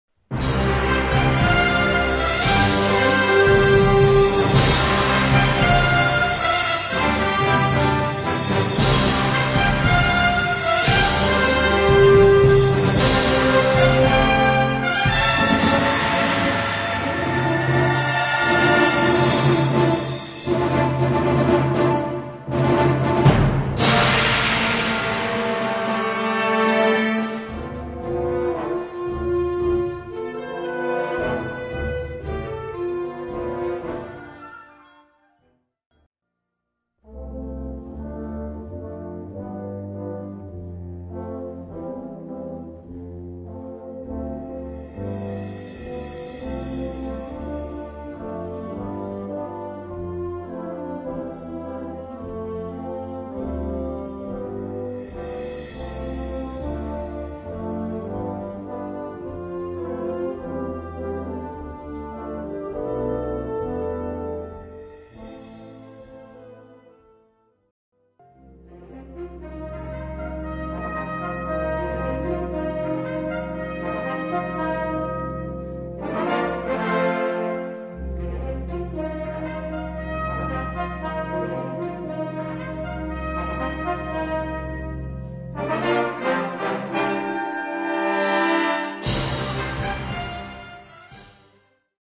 Category Concert/wind/brass band
Instrumentation Ha (concert/wind band)